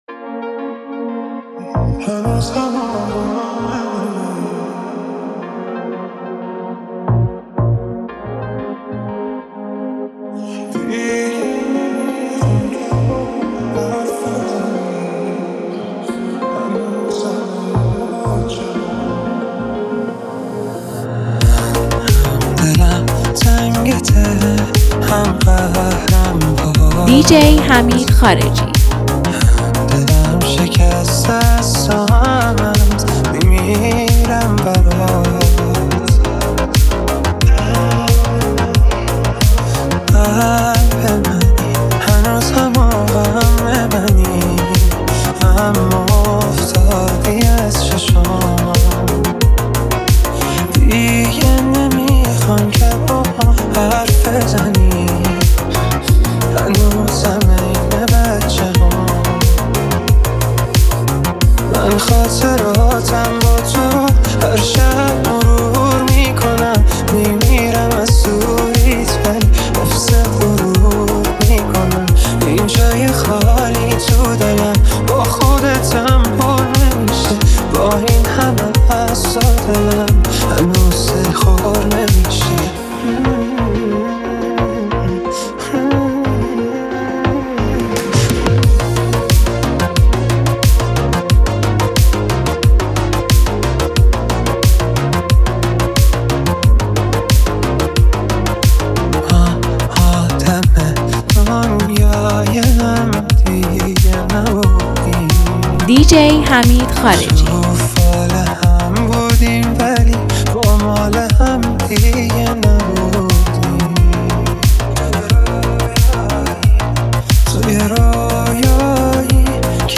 با سبک مدرن و خاص خود